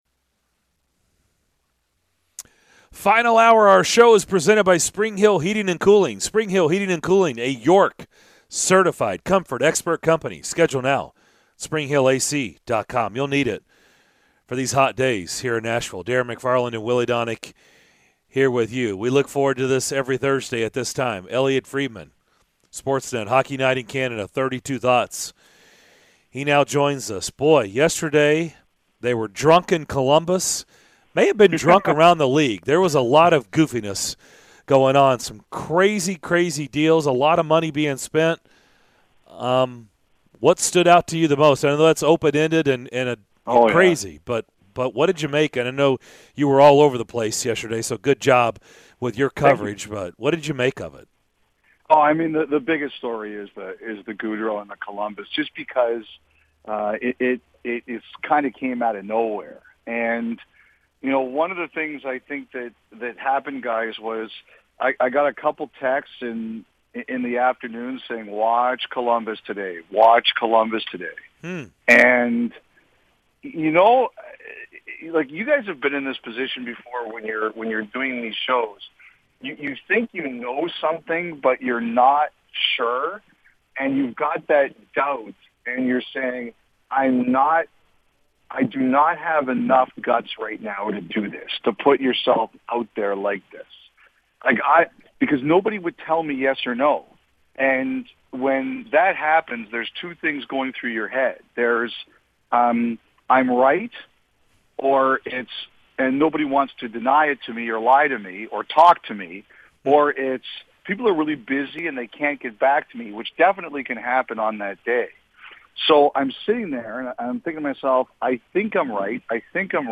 Elliotte Friedman Full Interview (07-14-22)